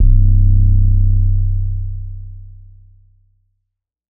SOUTHSIDE_808_skinny_Bb_C.wav